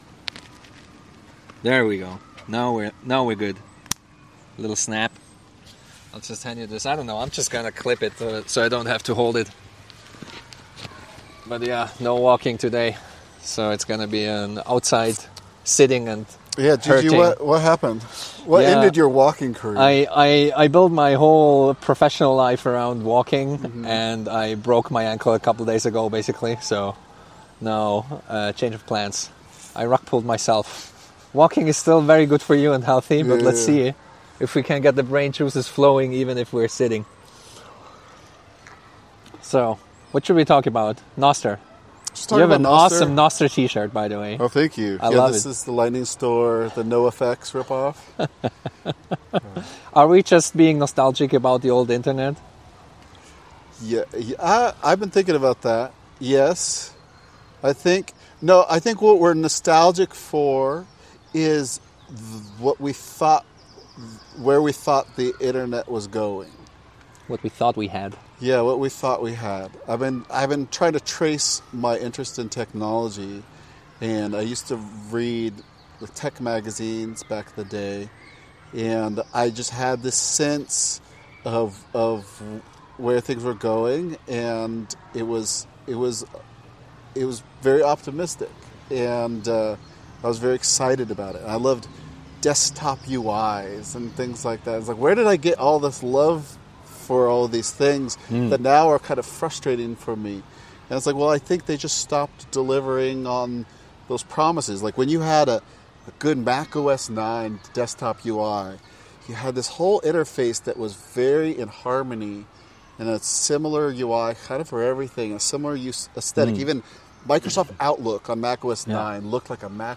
A walking dialog on what the right trade-off balance might be.